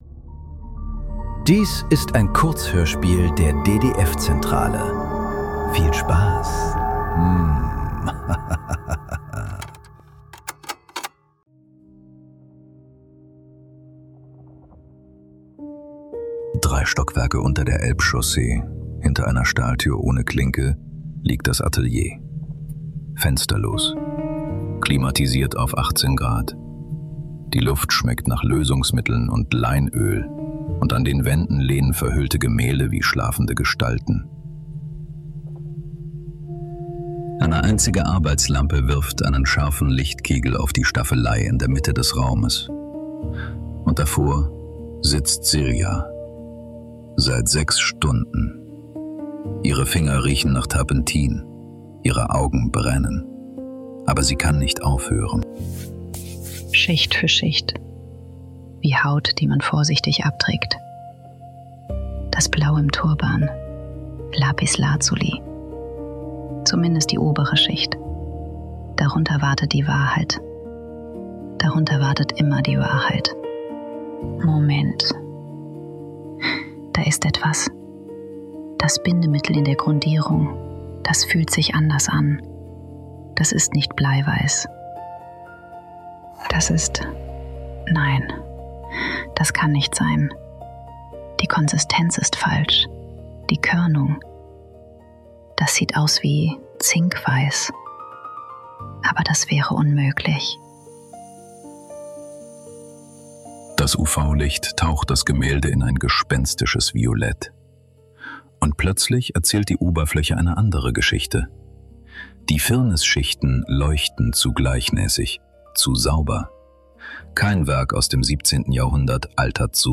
Bleiweiß ~ Nachklang. Kurzhörspiele.